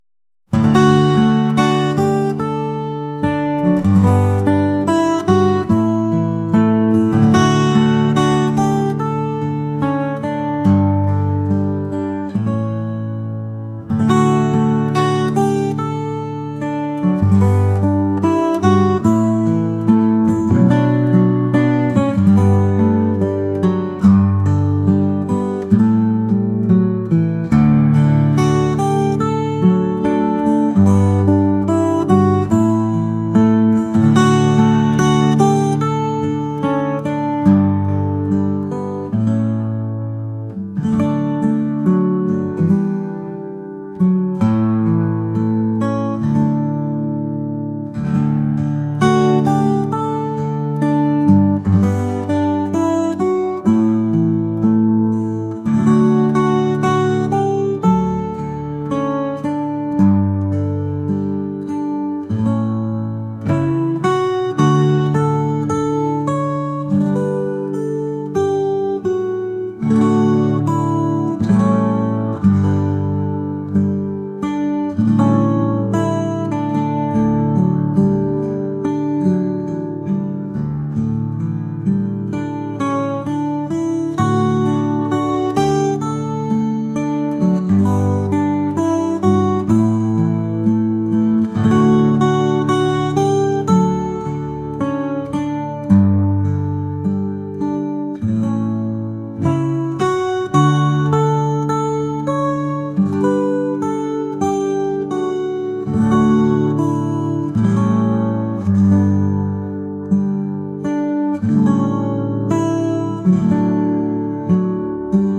acoustic | folk | laid-back